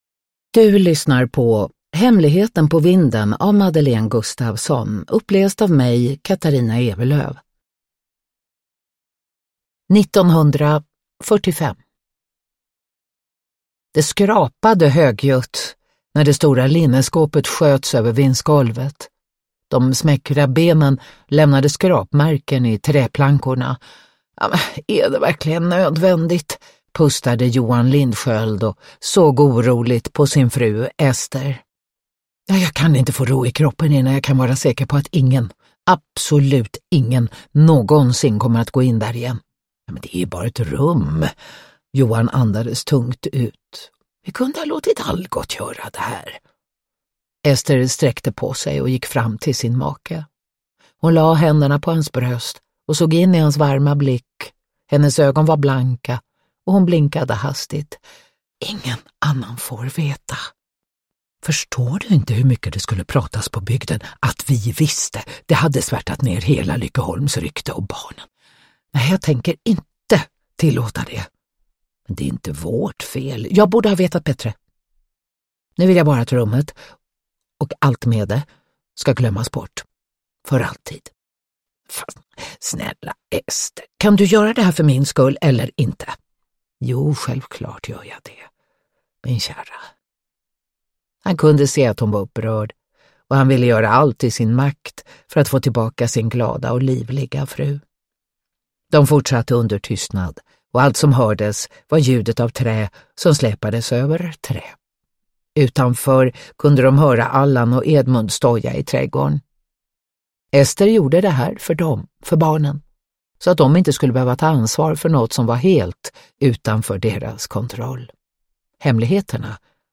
Hemligheten på vinden – Ljudbok
Uppläsare: Katarina Ewerlöf